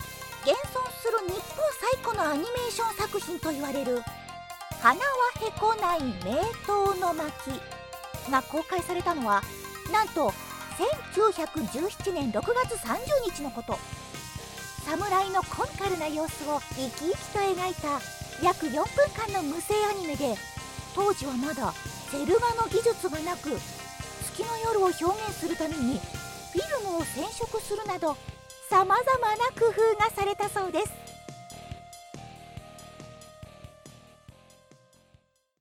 If you need bright clear, charming, healing, entertaining, warm, captivating, sophisticated, trustworthy, calm, magical Japanese voice
versatile, authentic, yet conversational, seductive, and charming voice.
Sprechprobe: Sonstiges (Muttersprache):